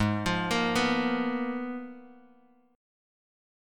Abm6add9 chord